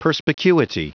Prononciation du mot perspicuity en anglais (fichier audio)